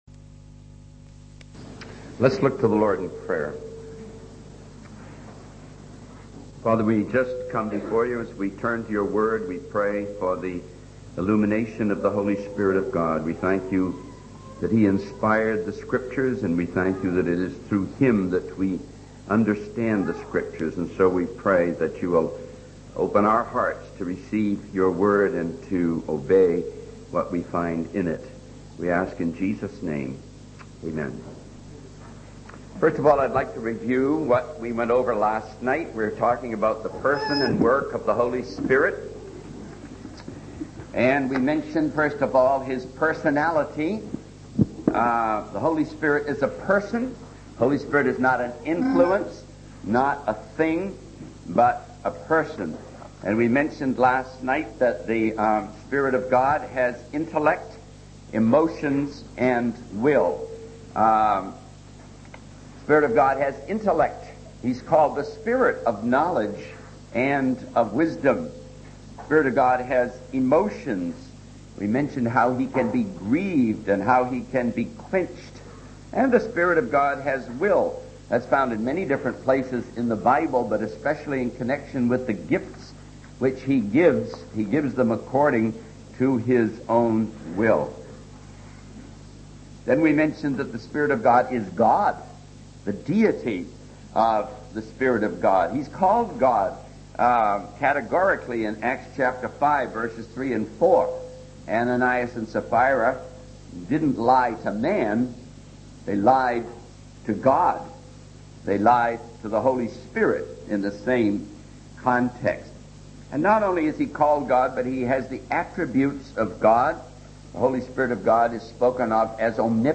In this sermon, the speaker emphasizes the importance of receiving the word of God with contrition and tears rather than with superficial joy.